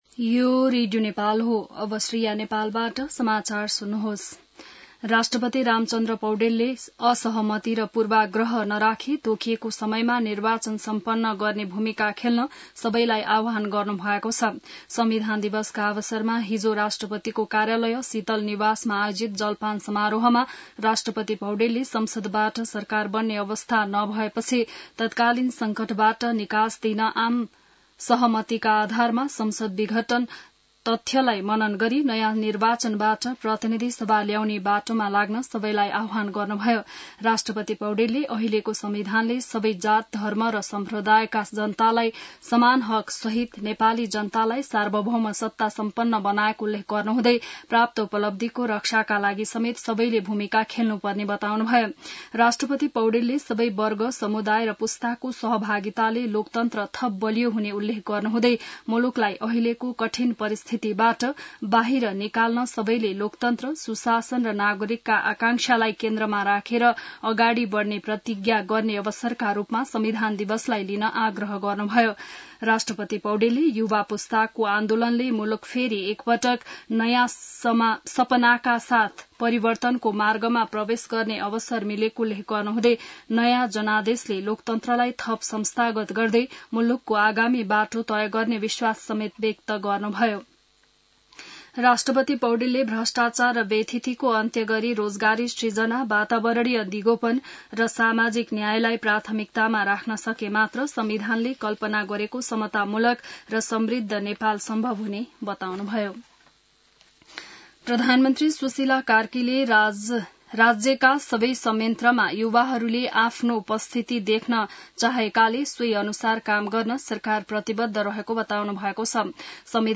बिहान ६ बजेको नेपाली समाचार : ४ असोज , २०८२